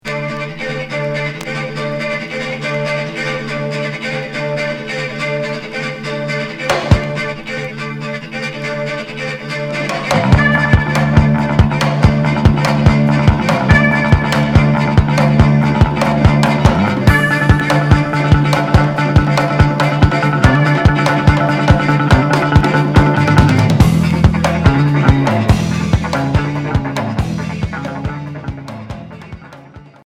New wave Premier 45t retour à l'accueil